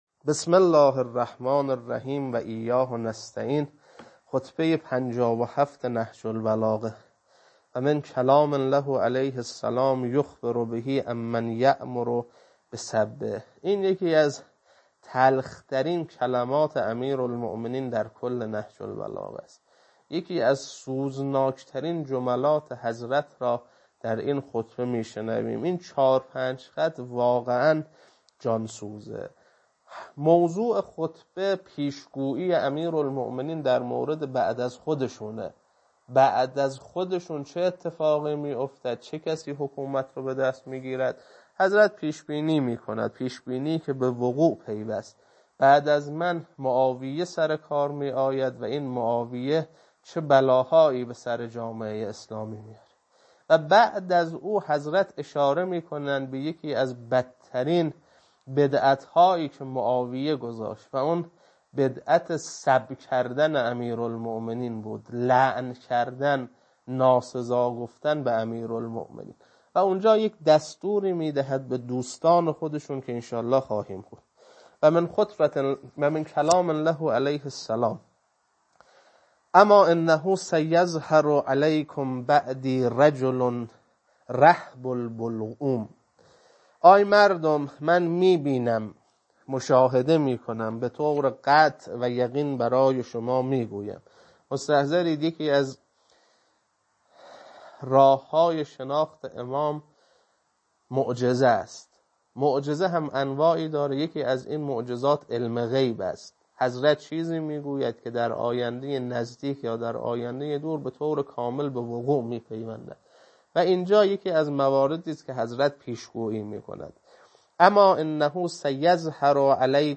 خطبه 57.mp3
خطبه-57.mp3